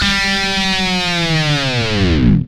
Index of /90_sSampleCDs/Roland L-CD701/GTR_GTR FX/GTR_E.Guitar FX